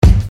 nt kick 13.wav